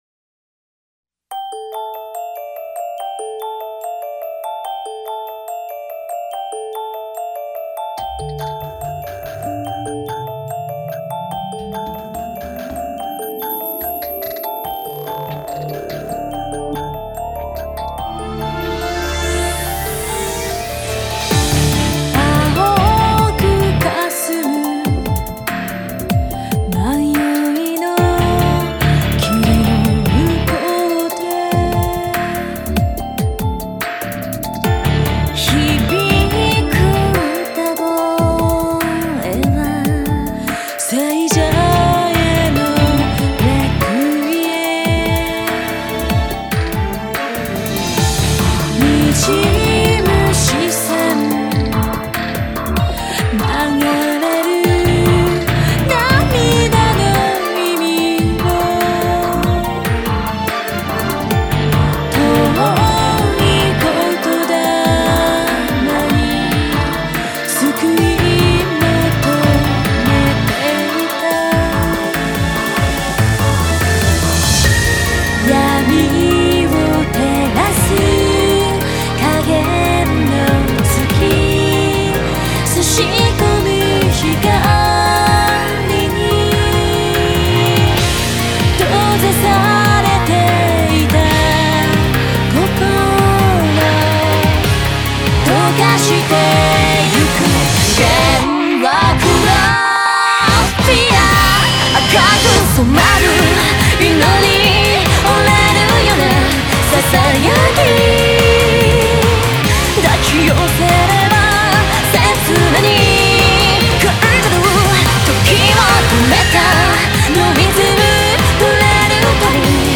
Барочное-лирическое-эпическое.